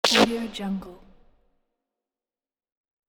دانلود افکت صدای نتیجه اشتباه
افکت صدای نتیجه اشتباه یک گزینه عالی برای هر پروژه ای است که به صداهای بازی و جنبه های دیگر مانند پاسخ، برنامه و غیره نیاز دارد.
Sample rate 16-Bit Stereo, 44.1 kHz
Looped No